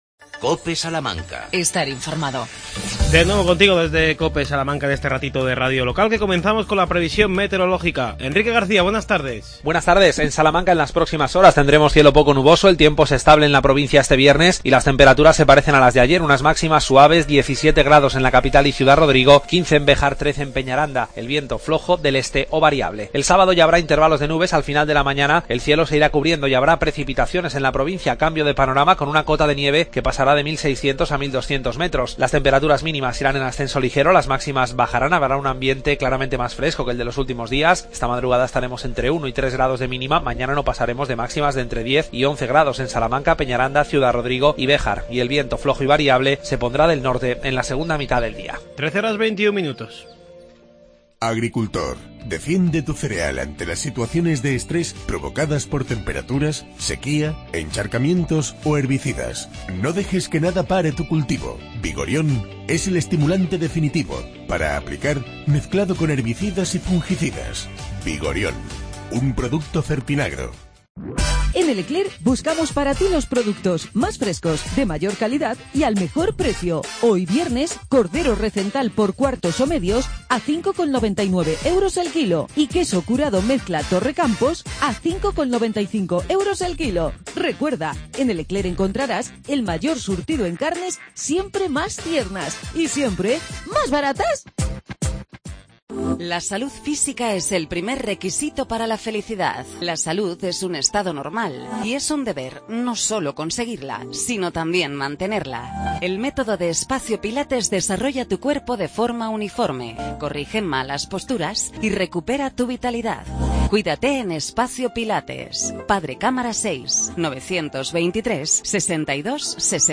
Redacción digital Madrid - Publicado el 17 feb 2017, 13:32 - Actualizado 19 mar 2023, 02:07 1 min lectura Descargar Facebook Twitter Whatsapp Telegram Enviar por email Copiar enlace 160 aficionados de los juegos de mesa miniaturistas se dan cita este fin de semana en Carbajosa. Hablamos con uno de los organizadores del campeonato.